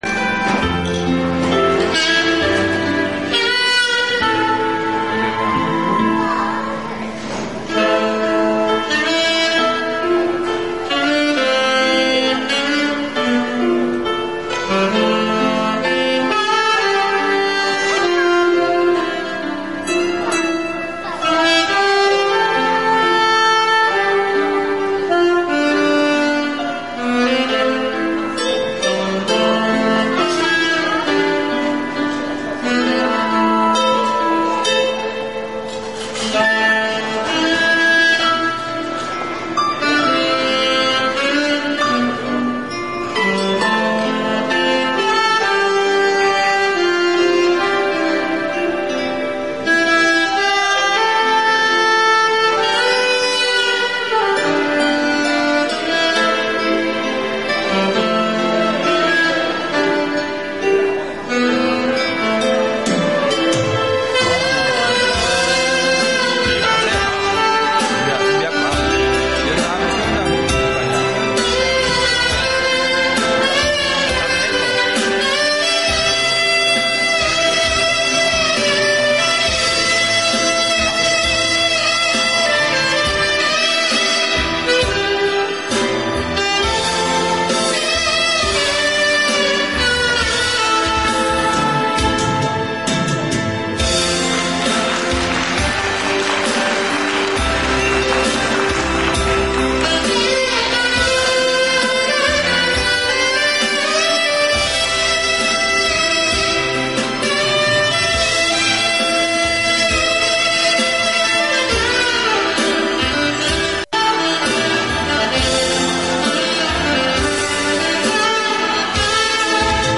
아무리 들어봐도 아직 귀가 열리지 않은 탓인지 칼톤 연주가 들리지 않습니다.
비브라토(vibrato) 도 거의 사용하지 않고...